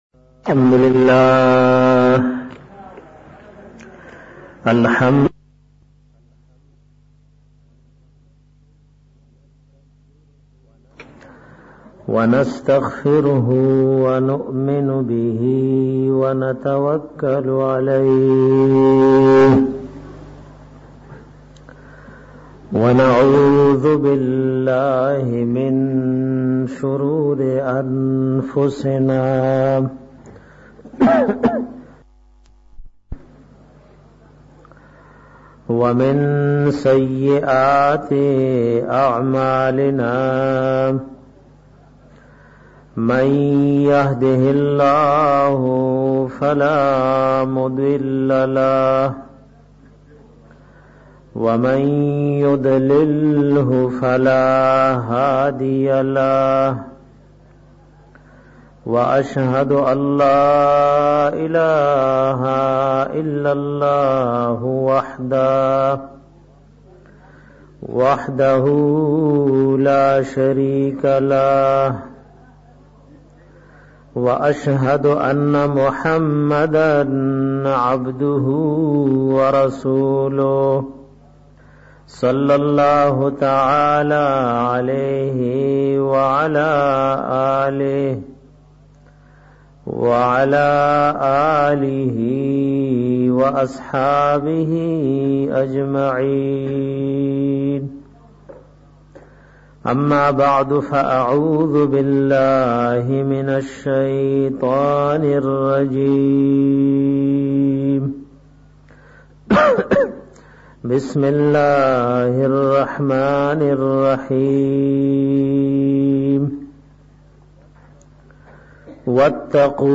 bayan pa moqa da iftita da madrasy oa muqam busti khail